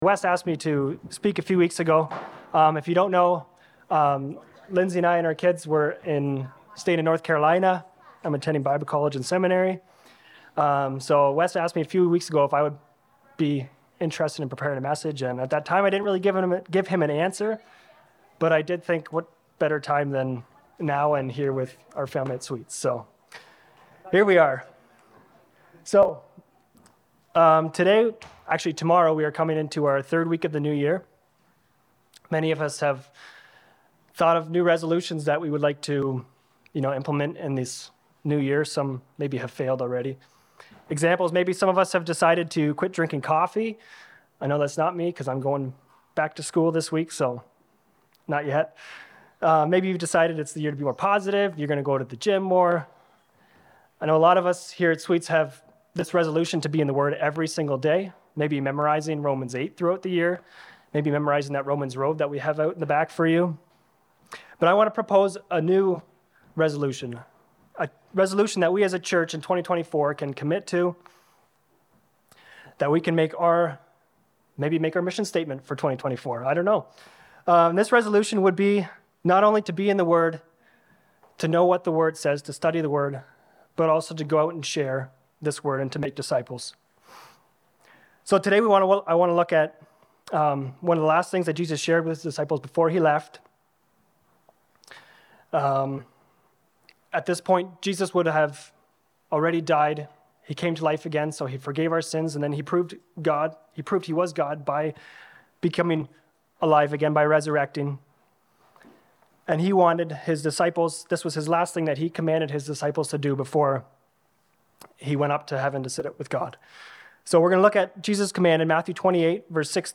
In this sermon, we explore the profound impact of one of Jesus' final instructions to His disciples - the Great Commission. As we examine Matthew 28:16-20, we unpack the three pivotal elements: The command, the promise, and how we can actively participate in this commission in our daily lives.